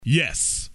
Tags: comedy announcer funny spoof crude radio